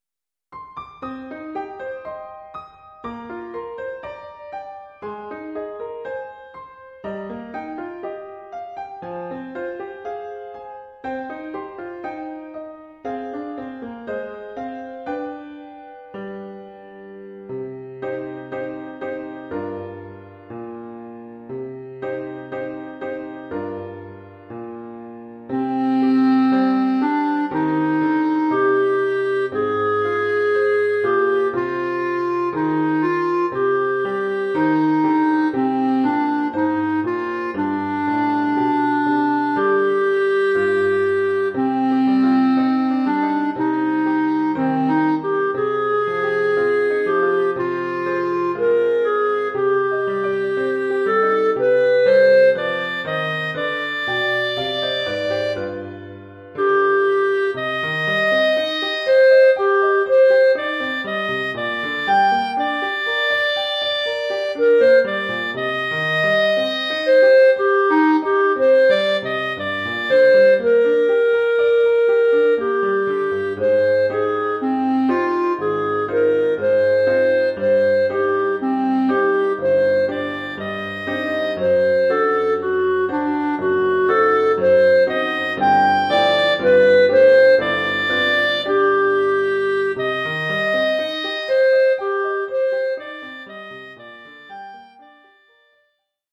Oeuvre pour clarinette et piano.